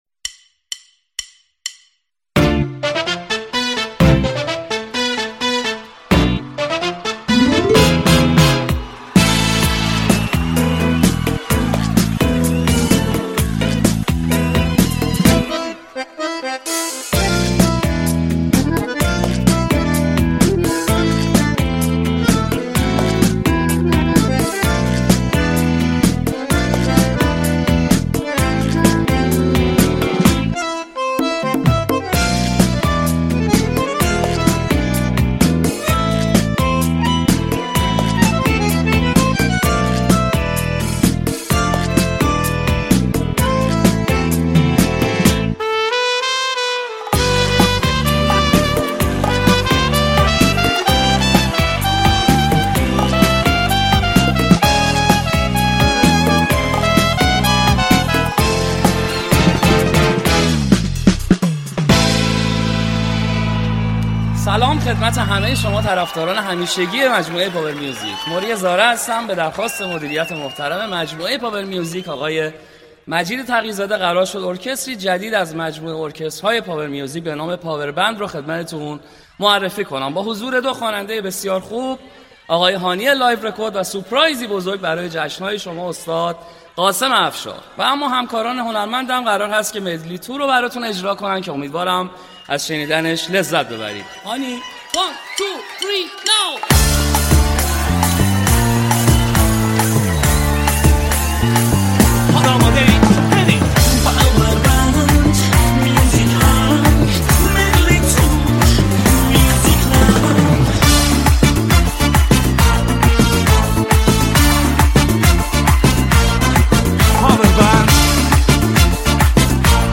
ریمیکس ارکستی بندی همراه با نی انبان
ریمیکس شاد و بندری مخصوص رقص